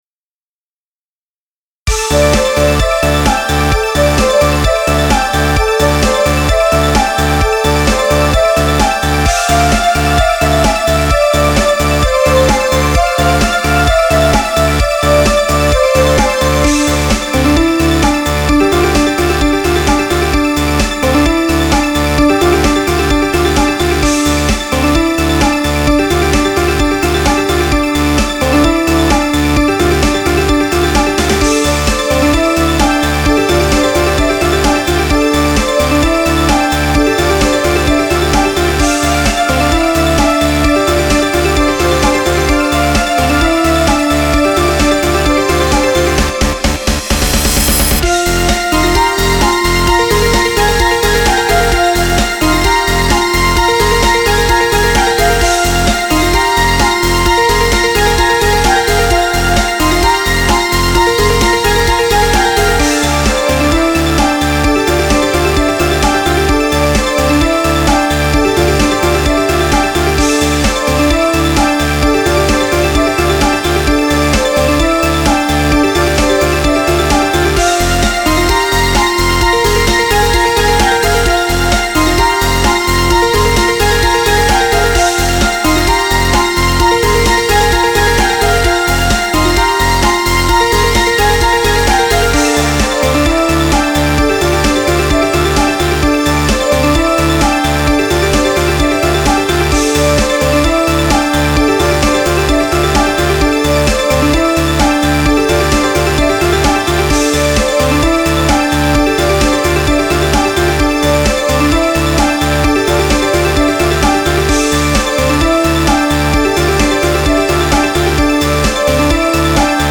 和風ハードコア.mp3